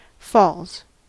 Ääntäminen
Synonyymit waterfall Ääntäminen US : IPA : [fɔːlz] Haettu sana löytyi näillä lähdekielillä: englanti Falls on sanan fall monikko.